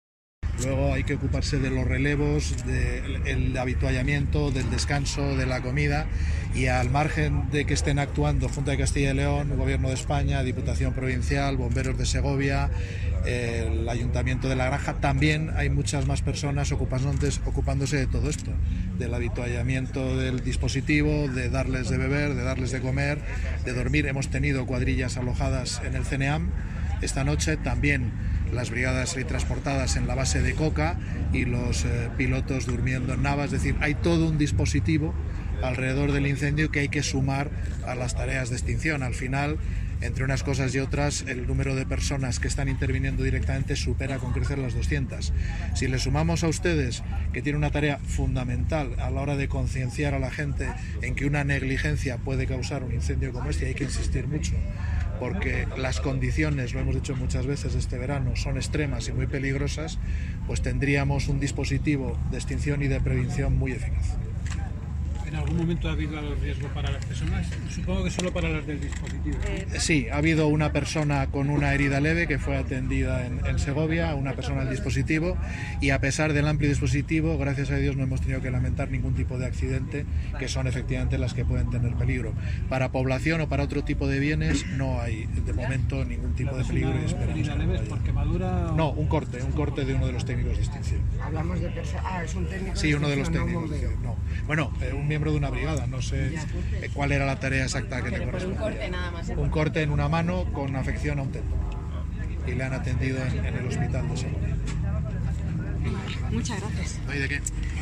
Intervención del delegado territorial.